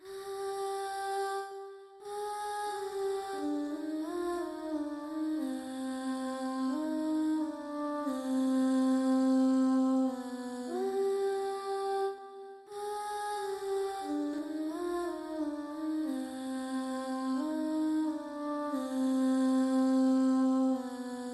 Tag: 90 bpm Trap Loops Vocal Loops 3.59 MB wav Key : Unknown